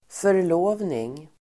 förlovning substantiv, engagement Uttal: [för_l'å:vning] Böjningar: förlovningen, förlovningar Definition: överenskommelse om giftermål Sammansättningar: förlovnings|ring (engagement ring) engagement substantiv, förlovning